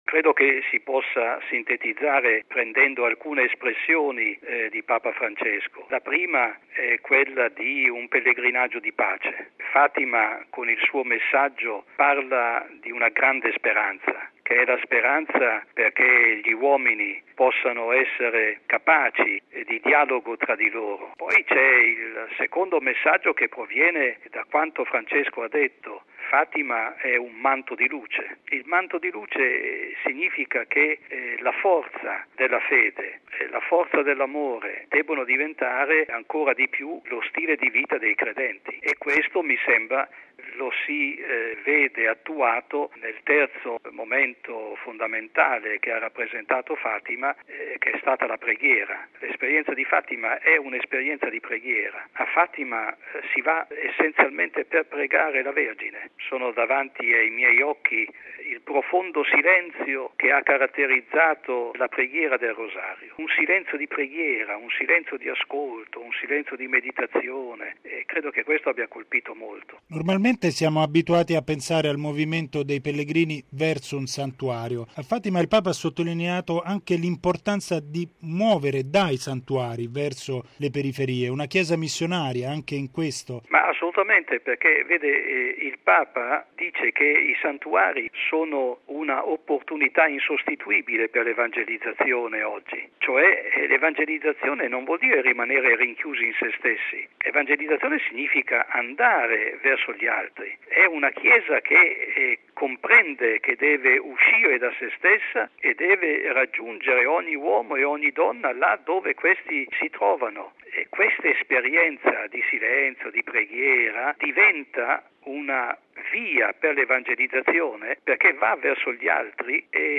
nell’intervista